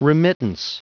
Prononciation du mot remittance en anglais (fichier audio)
Prononciation du mot : remittance